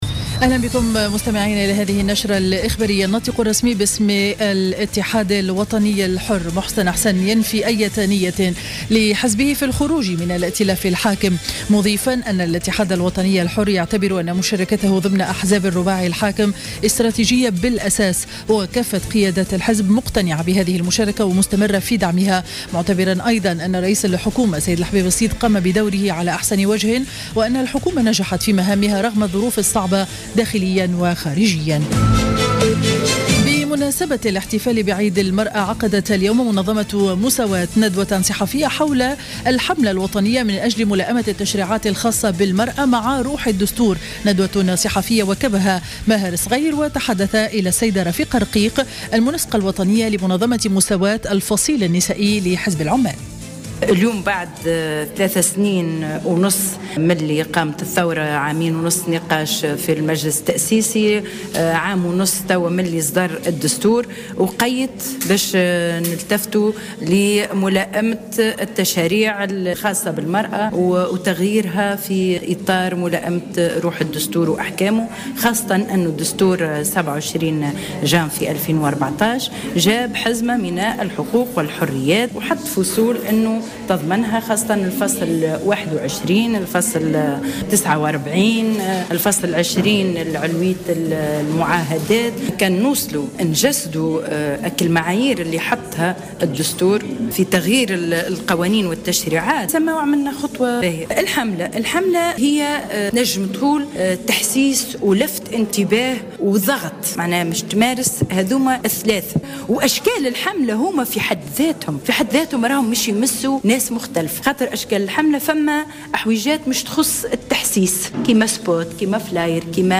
نشرة أخبار منتصف النهار ليوم الإربعاء 12 أوت 2015